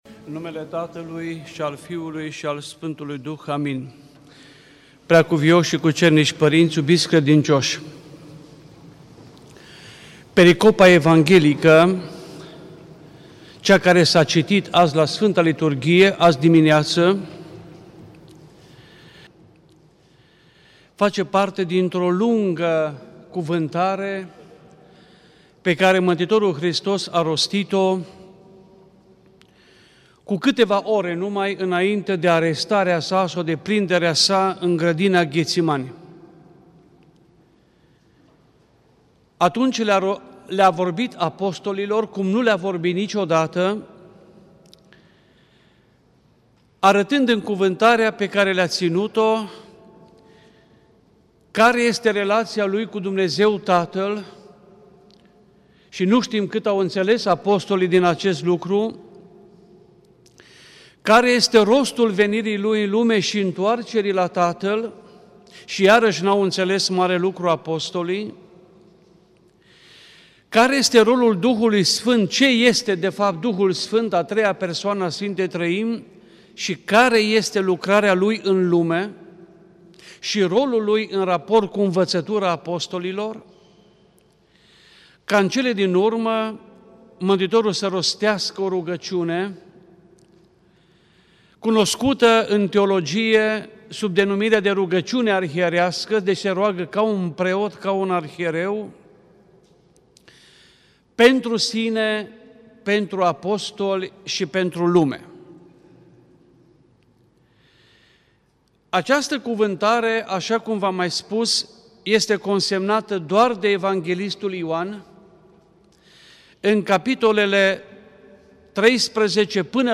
Predică la Duminica a 7-a după Paști
Cuvinte de învățătură Predică la Duminica a 7-a după Paști